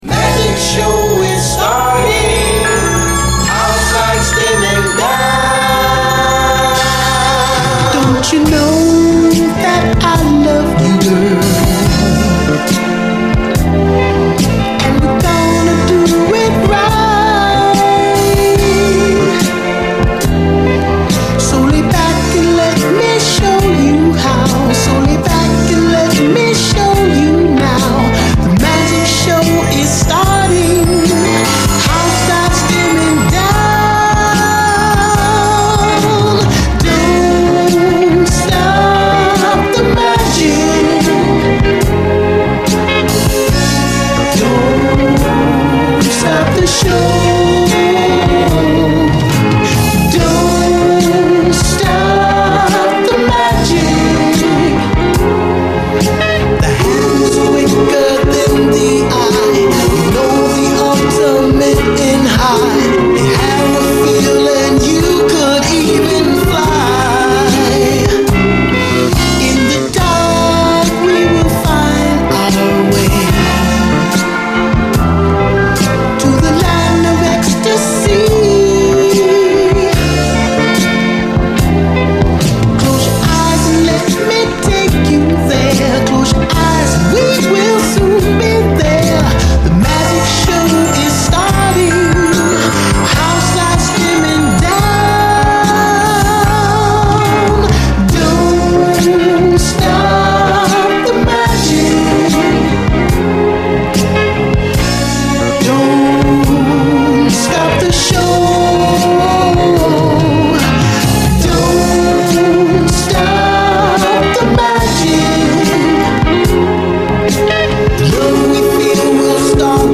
SOUL, 70's～ SOUL
70’S甘茶ソウル・スタイルが泣かせるレア・スウィート・モダン・ソウル12インチ！
81年とは思えない、コッテリと甘い70’Sスウィート・ヴォーカル・グループ・ソウル・スタイルが泣かせる甘茶ソウル！